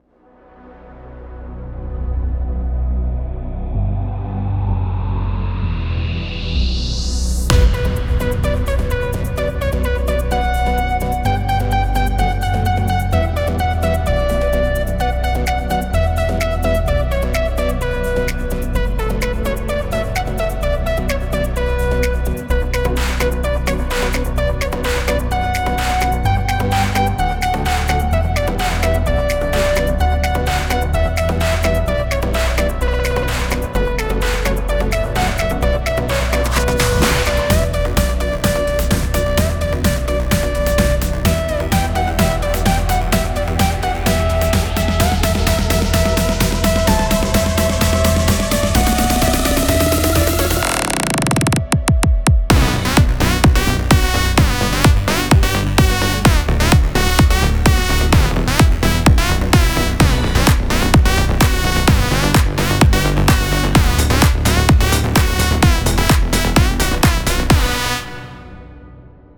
קורג-PA-700
סקיצה ניגון.wav
וואלה זה יצא ממש טוב במיוחד ההתחלה לדעתי הקלפים פה לא קשורים וצריך להחליף אותם והסאונדים מתחילת הבילדאפ עד הסוף זה סאונדים של אורגן והם קצת ישנים וגם צריך פה מיקס